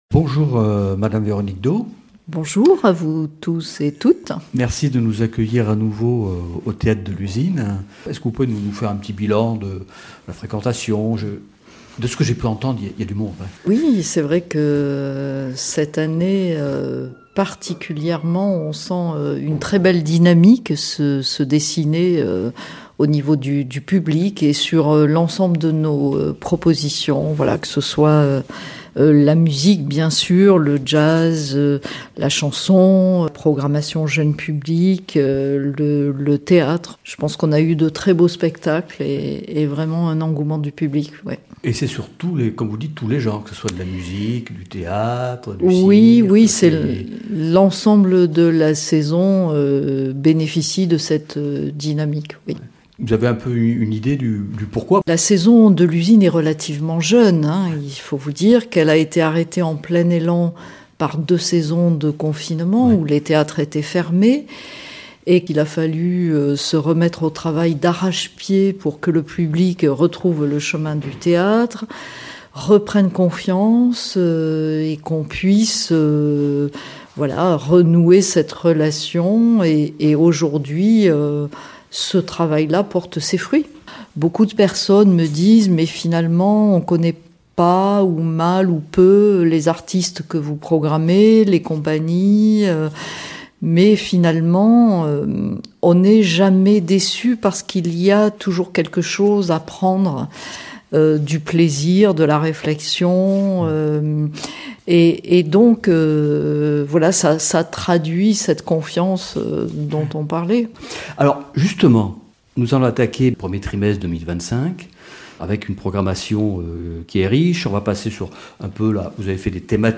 [ Rediffusion ] Aujourd'hui dans le Sujet du Jour